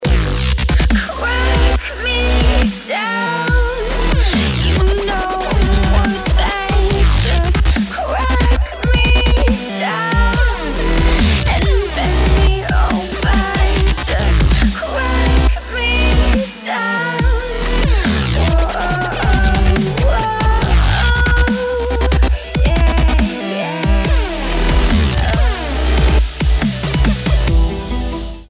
• Качество: 192, Stereo
Прекрасный дабстеп с очень красивым женским вокалом!